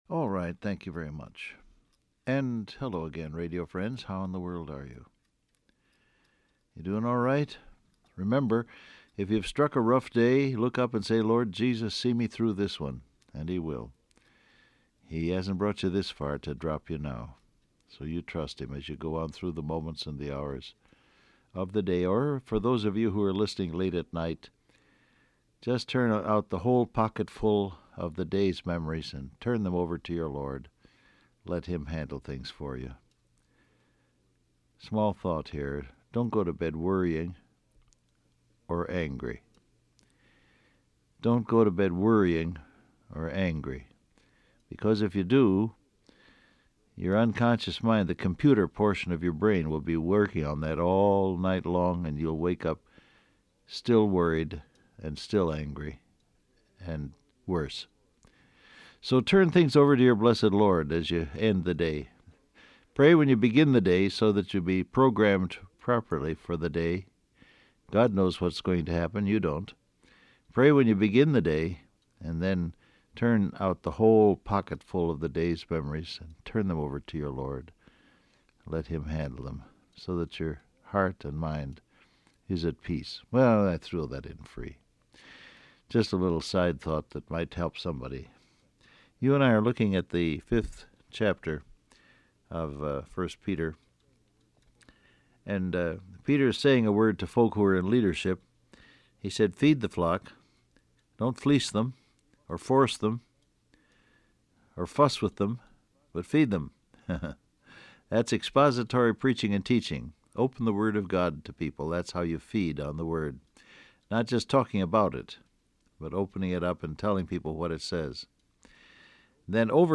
Download Audio Print Broadcast #7177 Scripture: 1 Peter 5:1-3 , 1 Timothy 4:12 Topics: Leadership , Love , Speech , Example , Lifestyle , Preaching Transcript Facebook Twitter WhatsApp Alright, thank you very much.